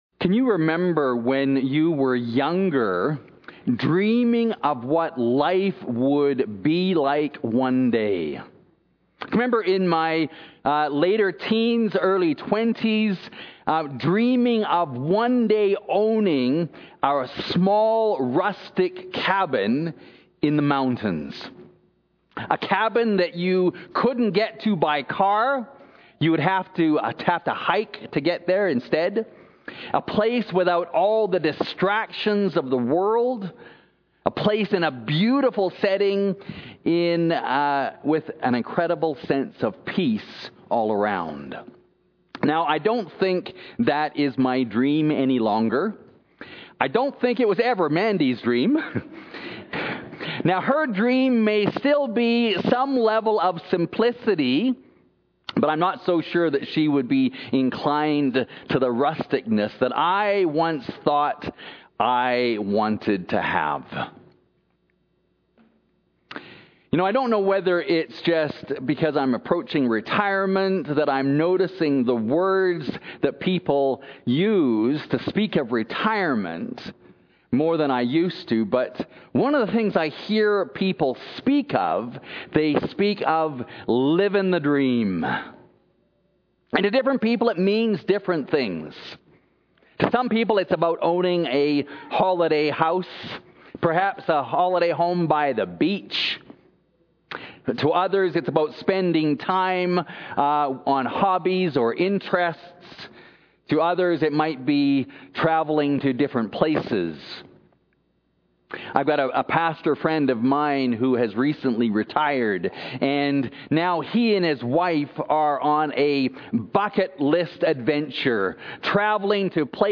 Sermon-May-7-2023.mp3